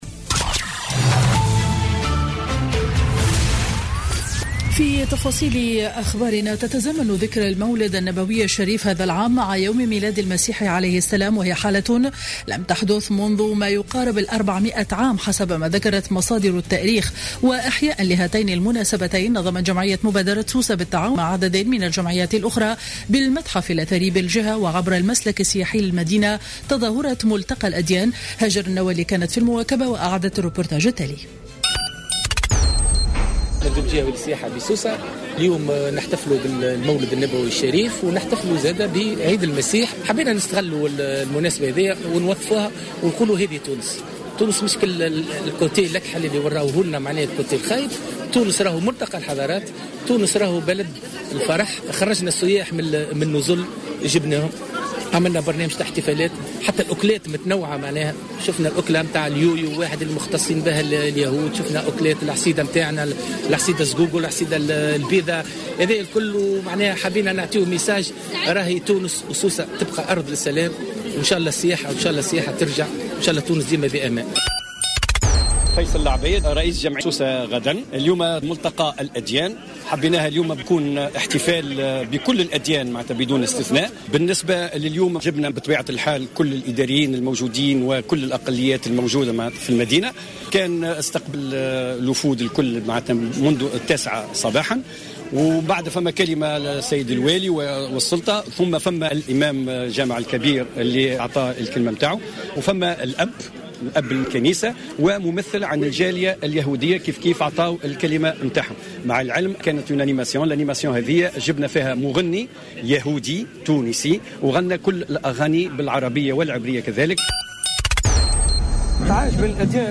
نشرة أخبار منتصف النهار ليوم الخميس 24 ديسمبر 2015